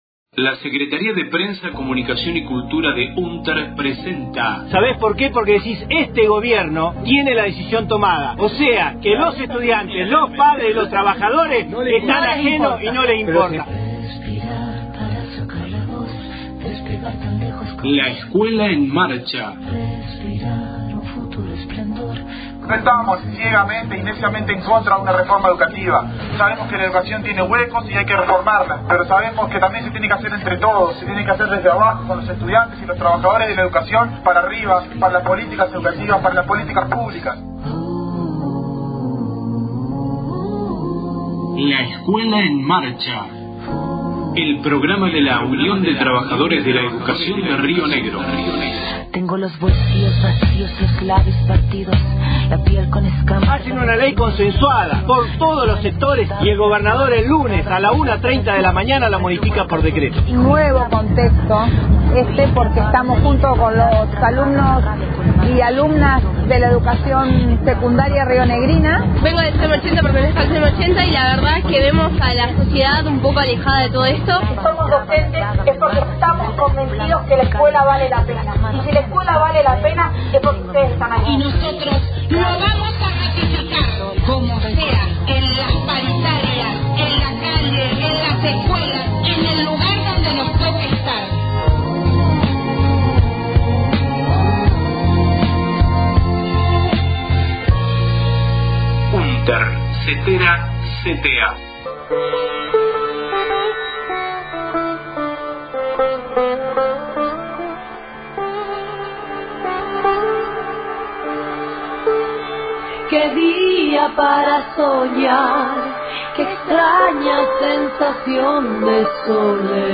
voces de la marcha por justicia completa para Carlos Fuentealba, a once años de su asesinato el 4/04/18 en Neuquén